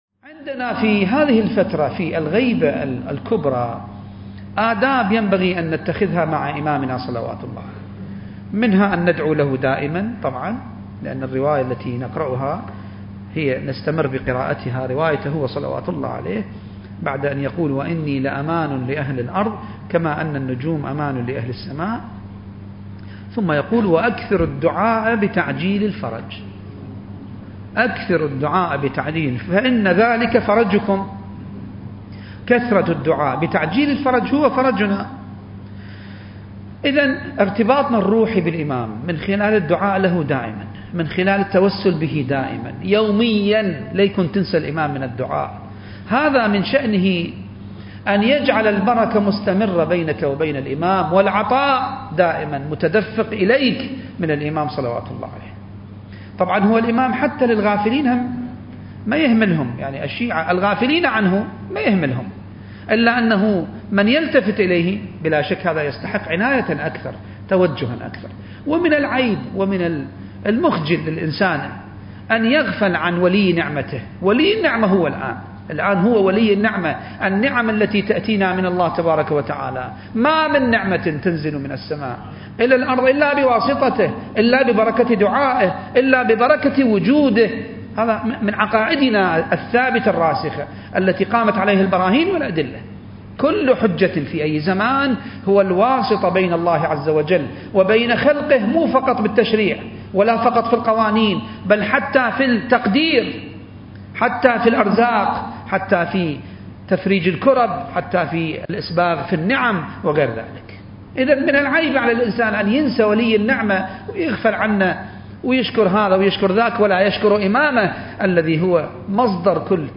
المكان: جامع الصاحب (عجّل الله فرجه) - النجف الأشرف التاريخ: 2021